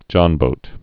(jŏnbōt) or john·boat (jŏnbōt)